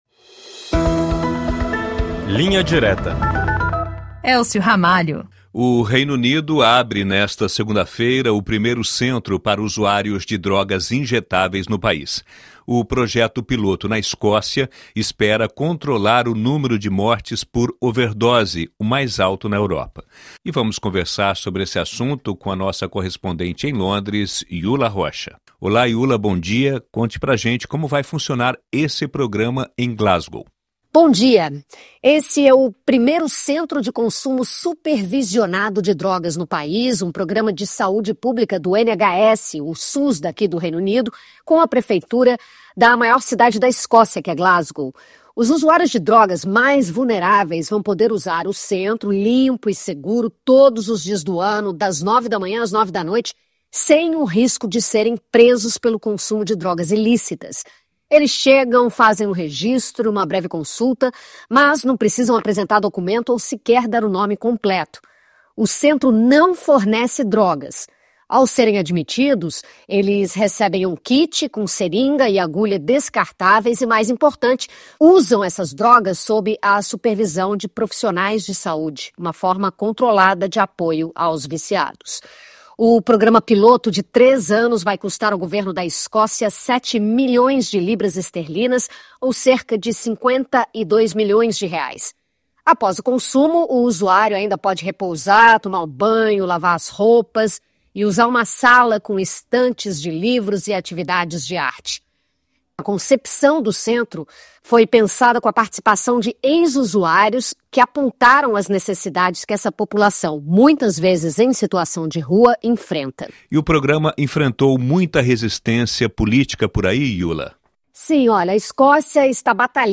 Bate-papo com os correspondentes da RFI Brasil pelo mundo para analisar, com uma abordagem mais profunda, os principais assuntos da atualidade.